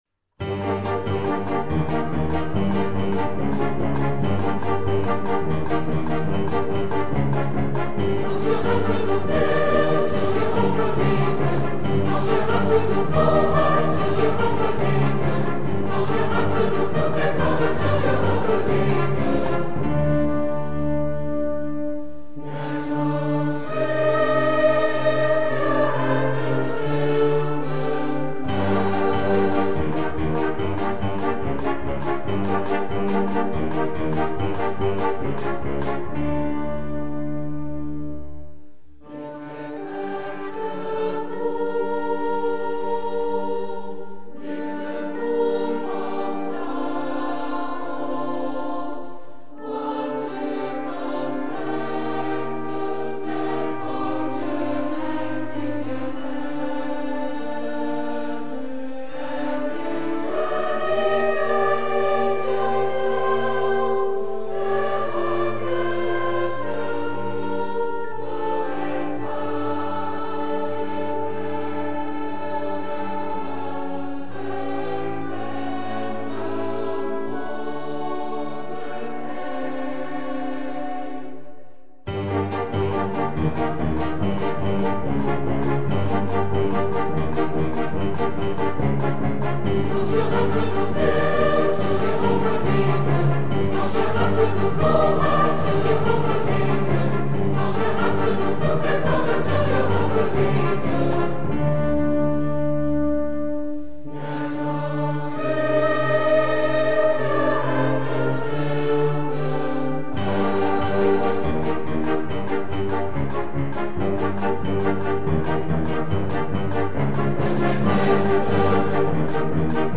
voor soli, gemengd koor, piano en harmonieorkest